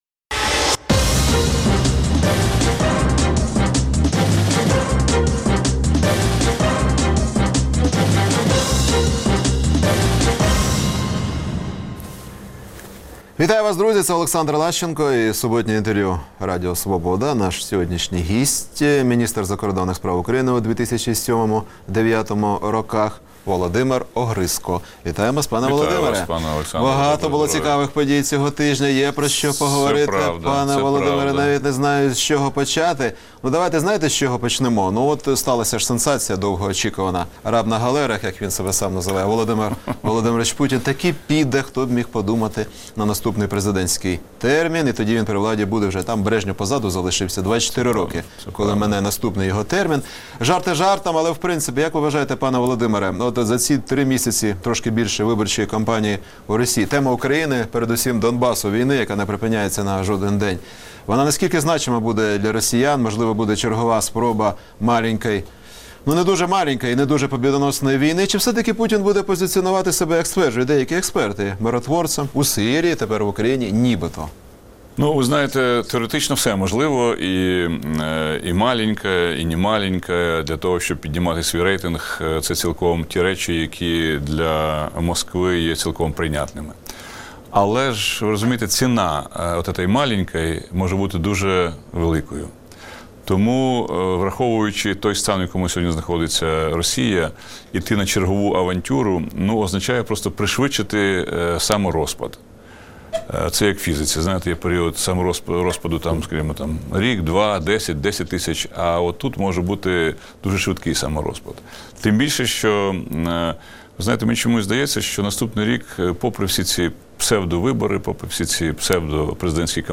Суботнє інтерв’ю - Володимир Огризко, міністр закордонних справ України у 2007–2009 роках